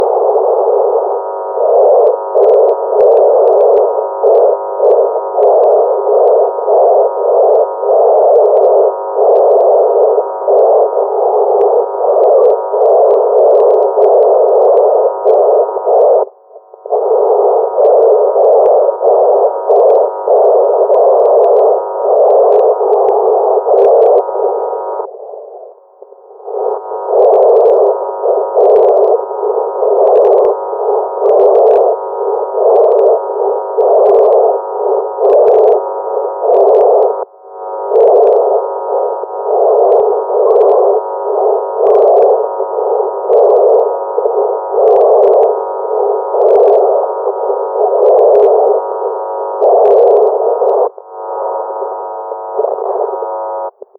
speak thinking induction loop set up 7th aug 2015
Recordings in the past to see if thinking can be heard under different conditions.